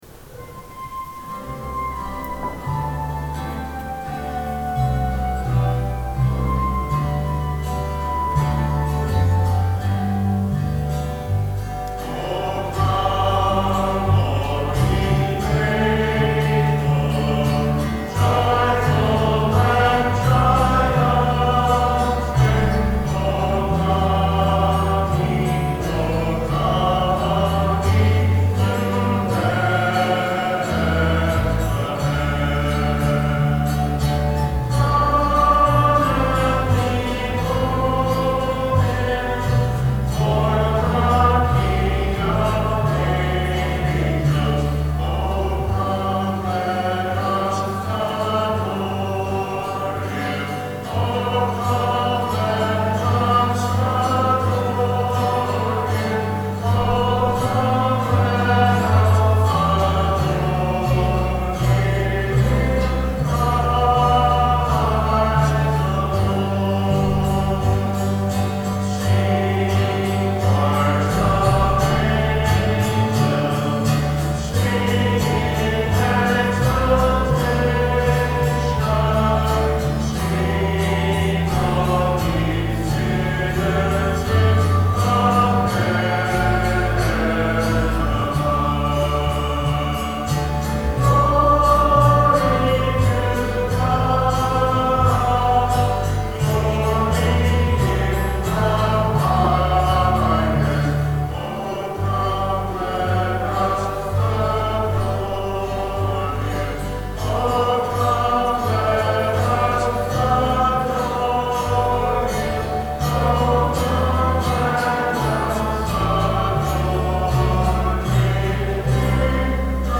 01/03/10 10:30 Mass Recording of Music - BK1030